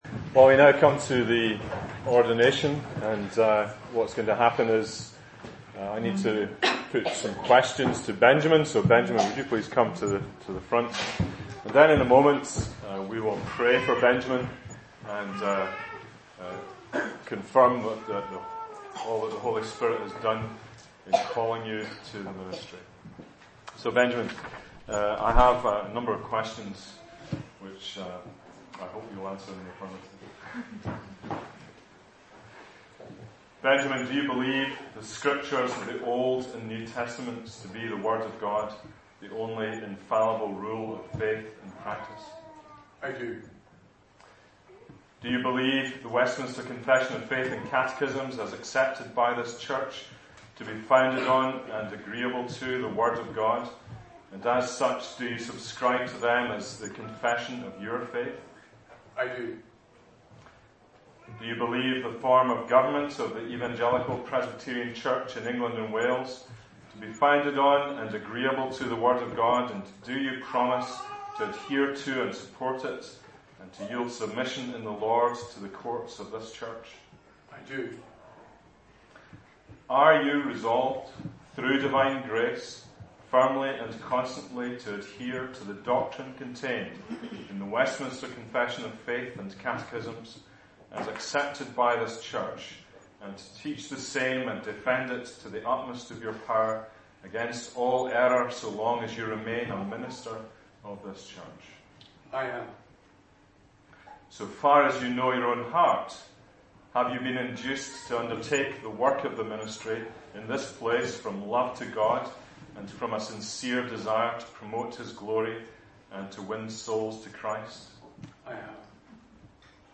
Special Services